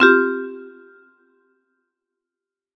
search_updated.ogg